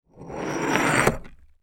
Sand_Pebbles_28.wav